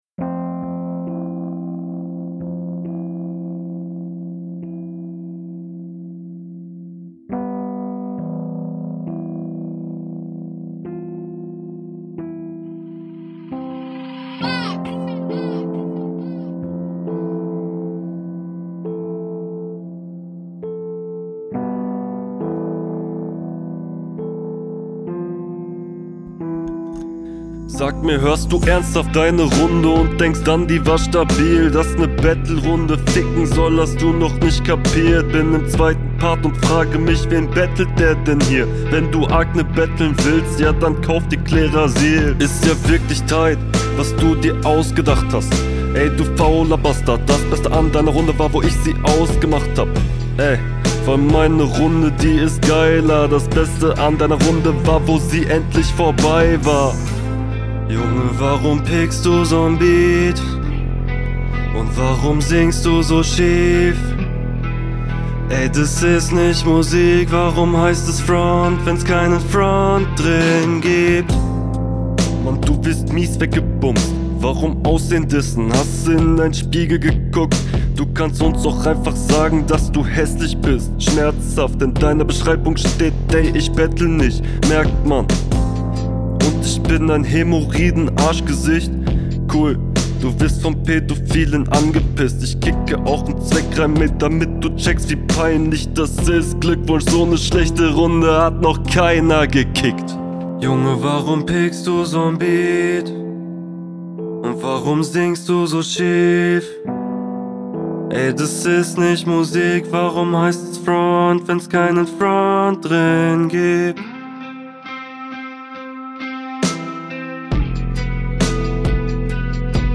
patterns echt cool, hol dir mal ne ordentliche mische, klingt leider recht raw mit hall